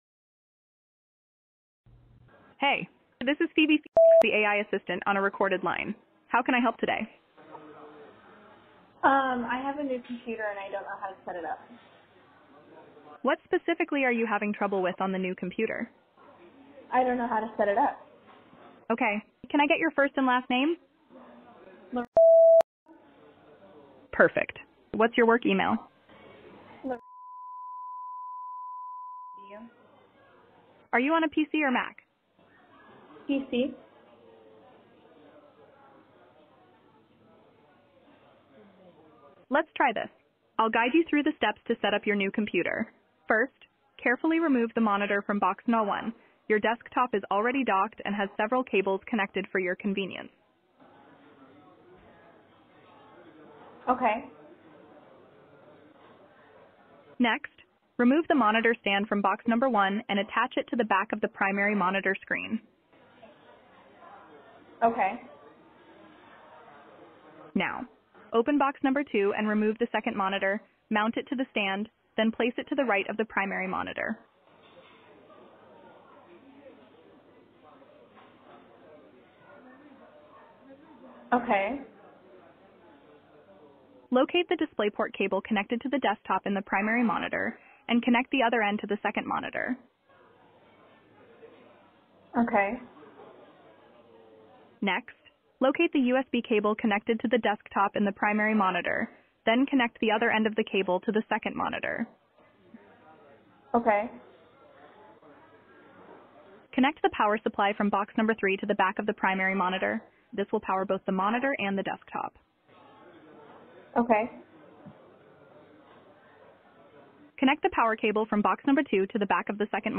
An IT Problem handled over the Phone by our AI VOICE AGENT